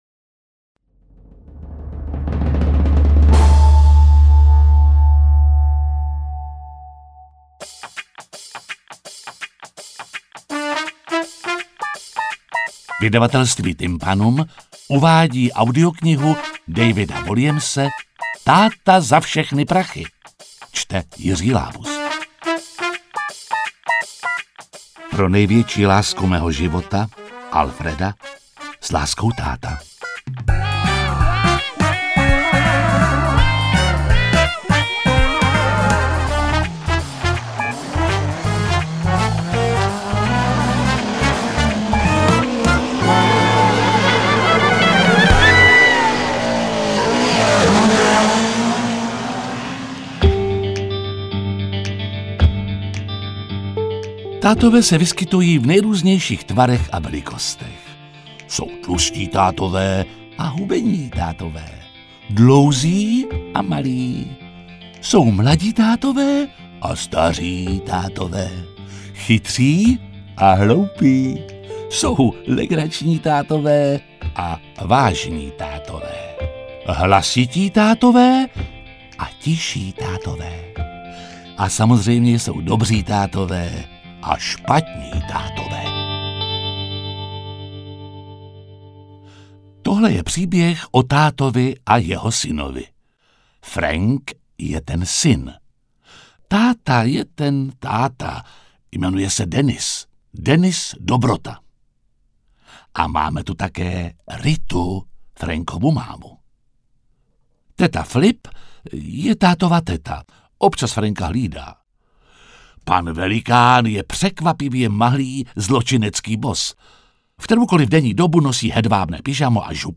Interpret:  Jiří Lábus